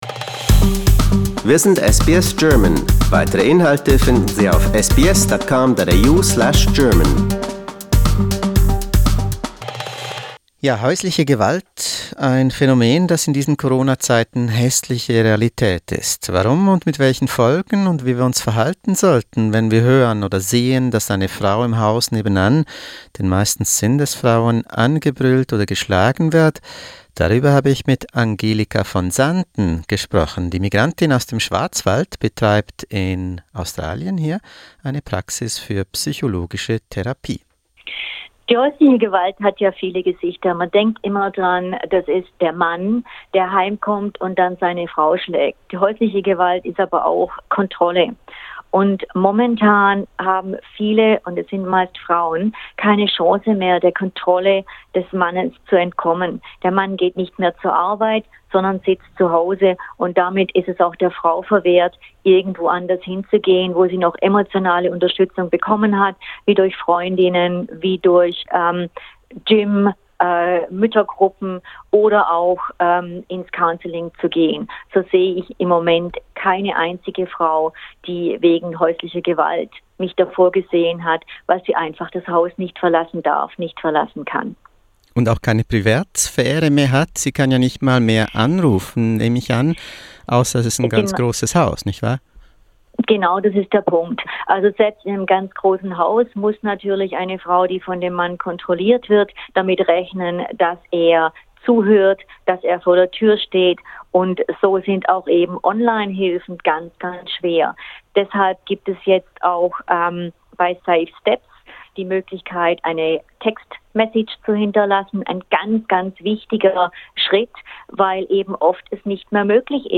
Skype-Interview mit SBS